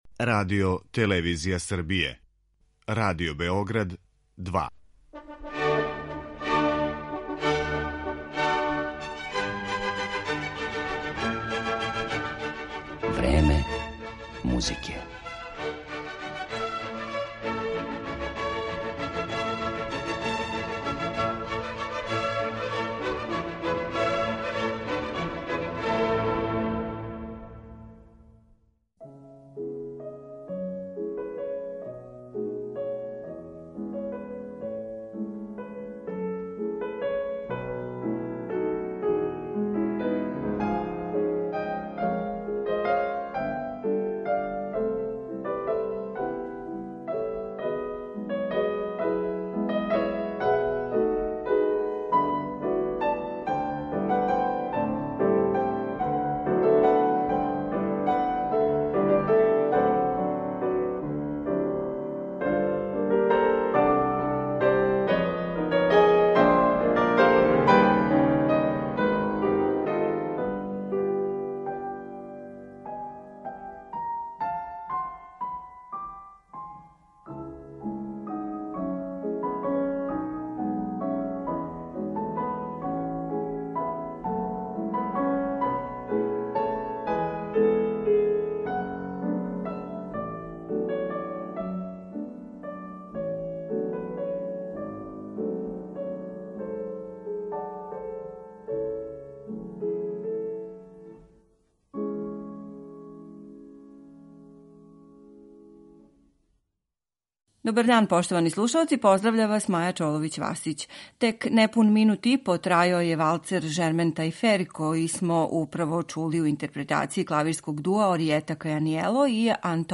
Снимак са концерта
дела за два клавира
клавирског дуа